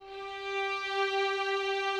strings_055.wav